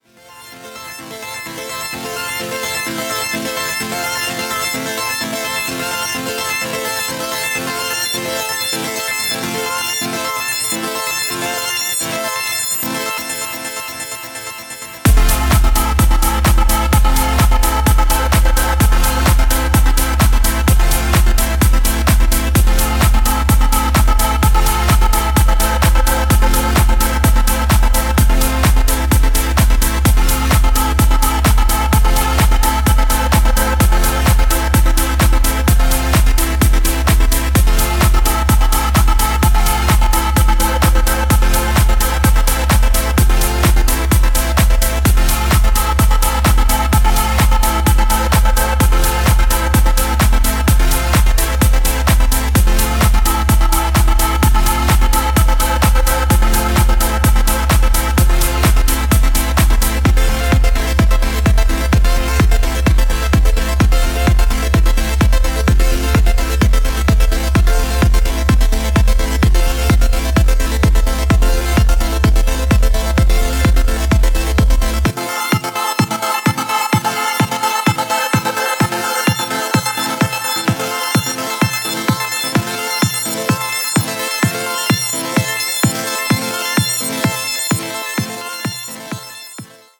B面も負けず劣らずな展開で、FMシンセとヴォーカルフレーズをリフレインさせながらフリーキーに進行していきます。